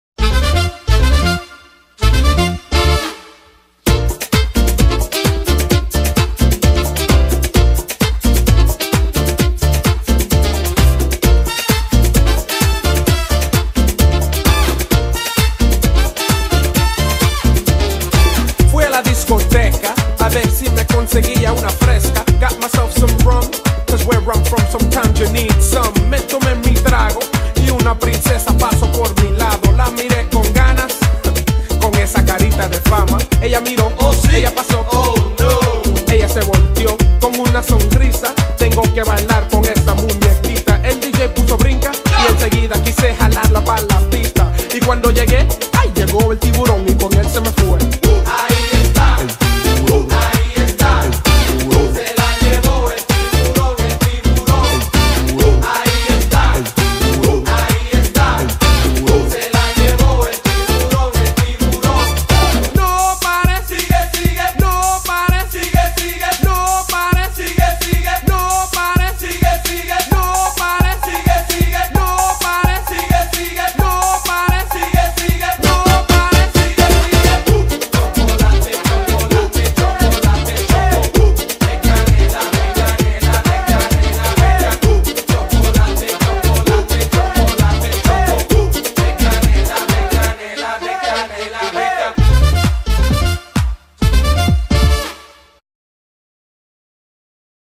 BPM130
Audio QualityMusic Cut
A great blend of hip hop, latino and Miami bass!